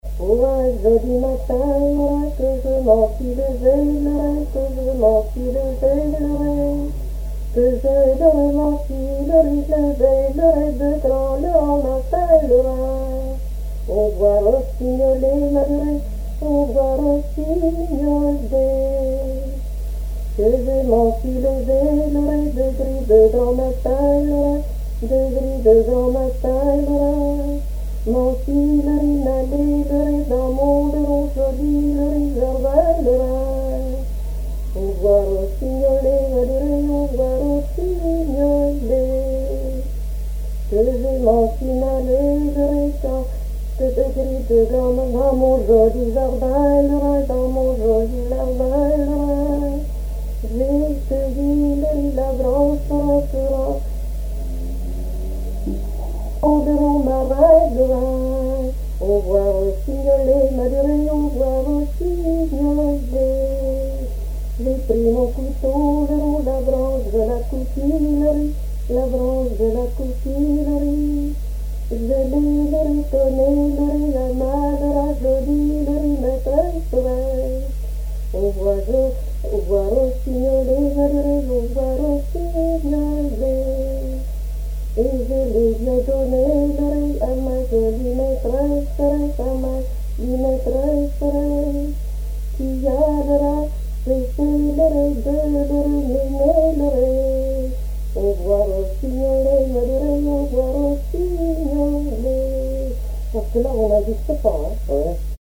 Genre énumérative
Enquête Tap Dou Païe et Sounurs
Pièce musicale inédite